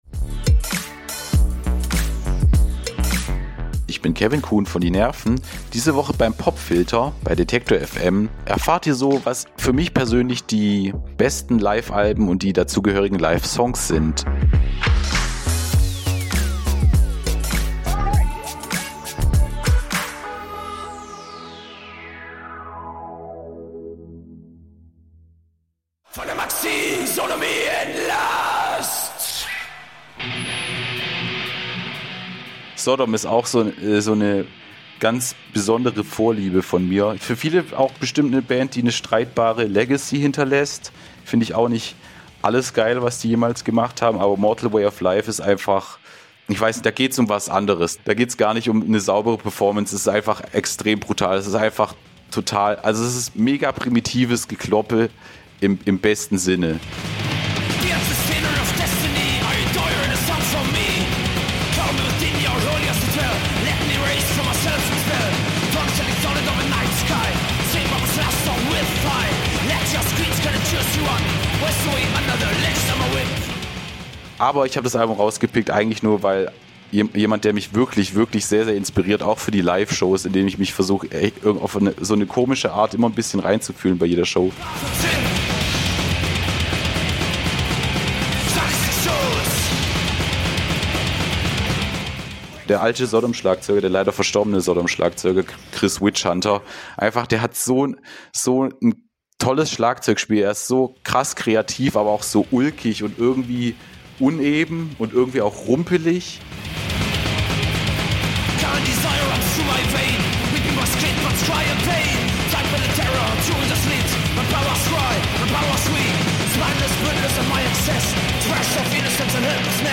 Dezember 2024 Nächste Episode download Beschreibung Teilen Abonnieren Heute wird es richtig laut.